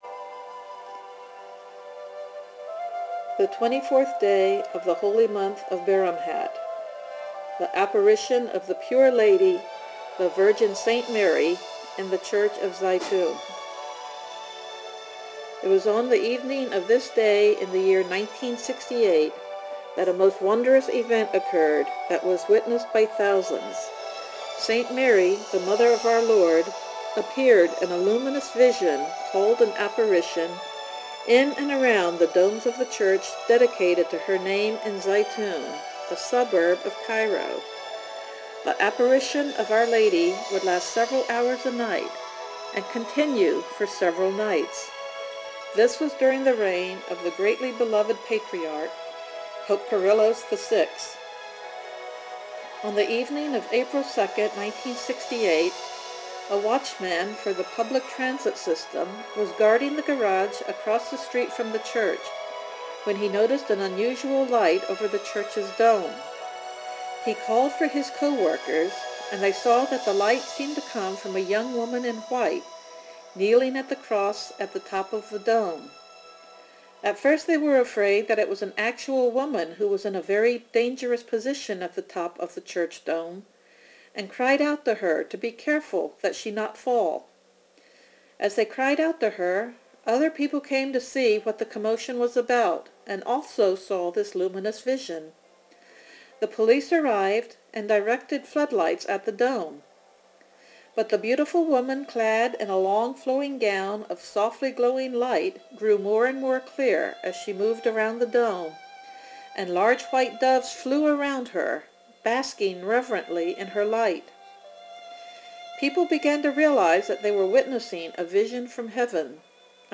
Synaxarium reading for 20th of Baramhat